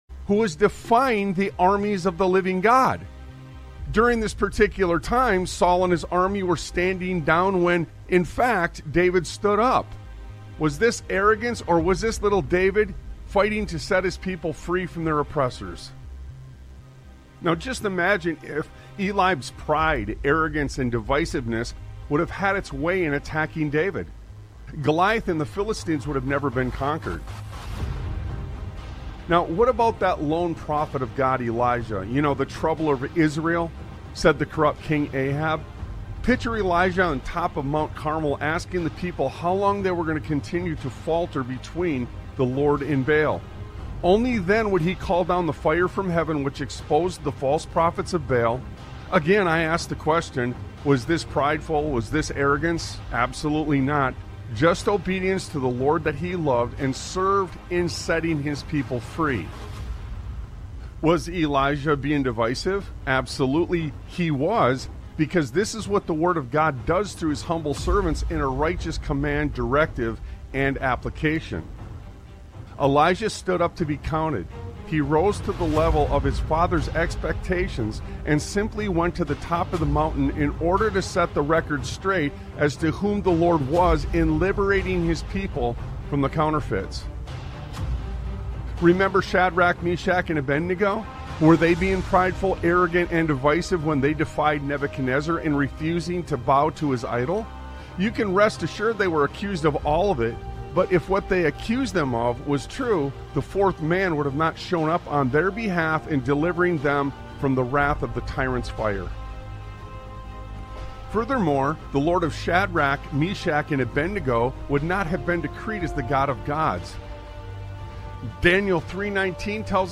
Talk Show Episode, Audio Podcast, Sons of Liberty Radio and Setting the Stage on , show guests , about Setting the Stage, categorized as Education,History,Military,News,Politics & Government,Religion,Christianity,Society and Culture,Theory & Conspiracy